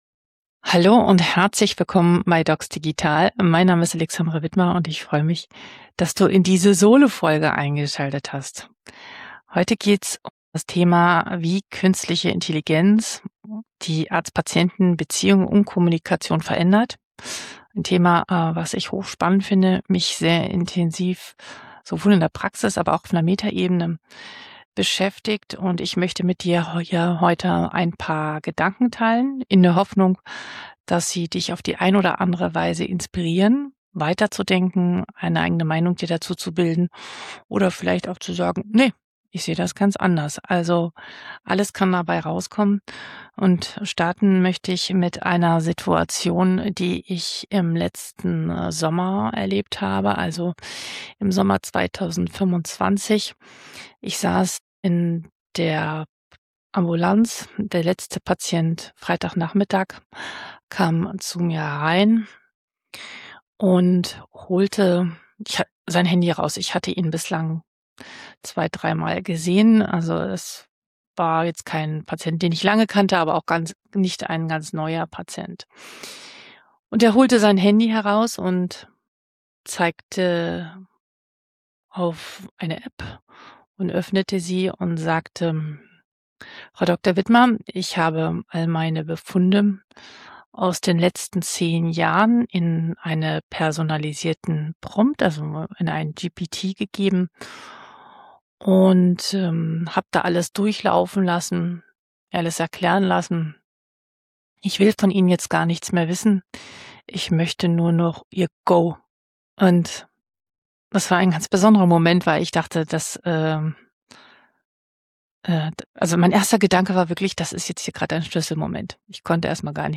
In dieser Solofolge teile ich persönliche Praxiserlebnisse und analysiere, warum Gespräche heute nicht mehr bei Symptomen beginnen, sondern bei Entscheidungswegen.